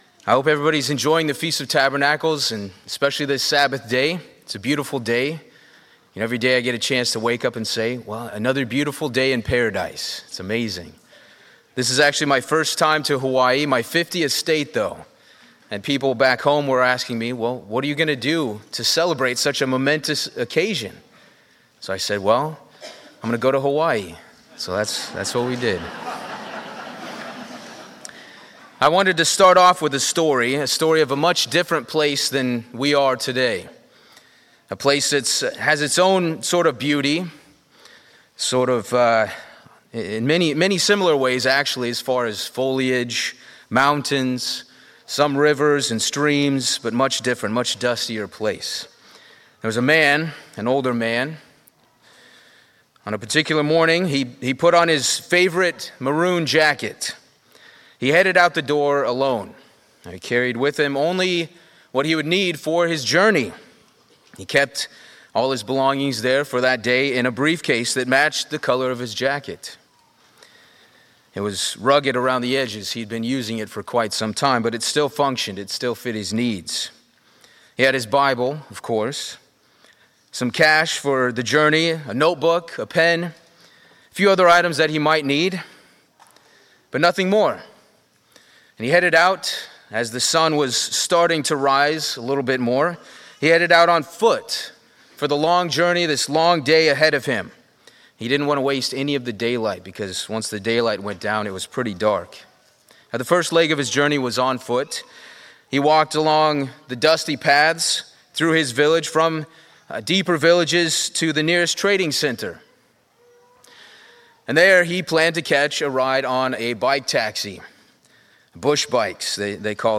Sermons
Given in Lihue, Hawaii